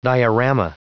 Prononciation du mot diorama en anglais (fichier audio)
Prononciation du mot : diorama